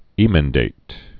(ēmĕn-dāt, ĭ-mĕn-)